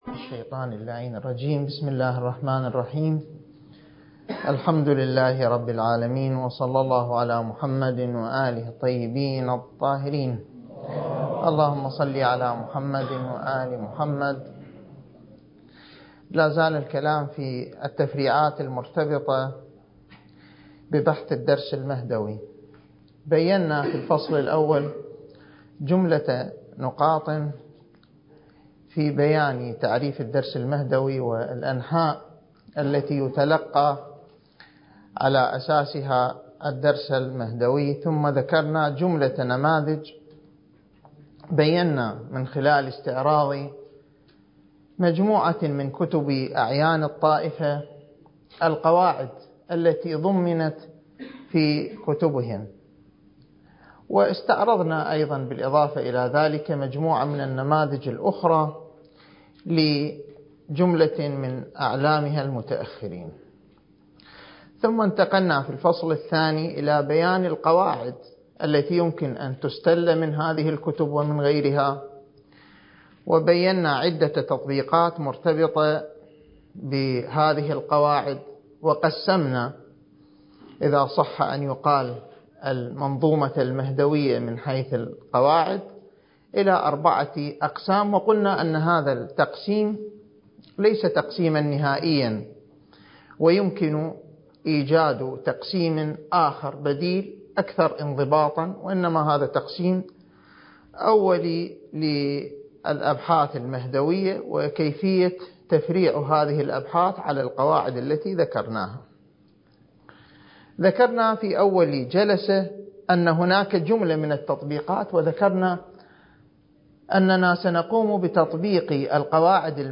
(المحاضرة التاسعة والعشرون)
المكان: النجف الأشرف